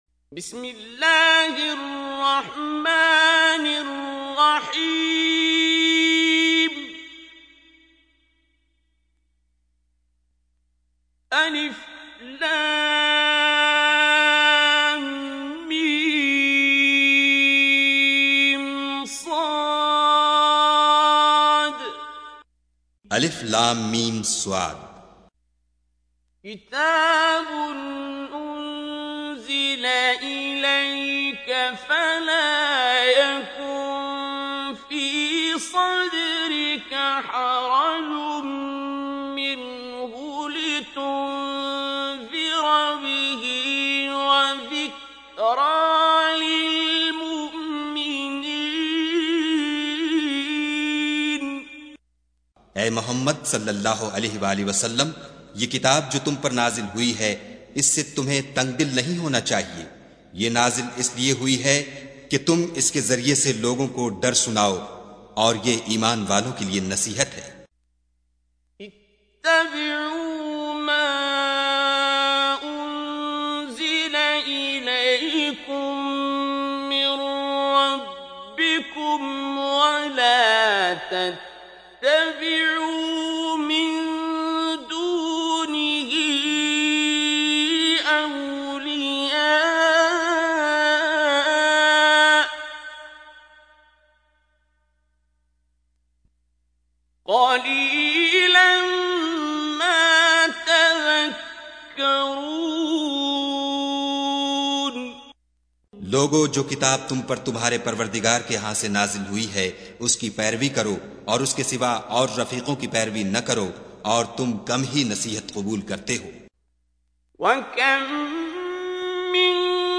Listen online and download beautiful tilawat and urdu translation of Surah Al Araf.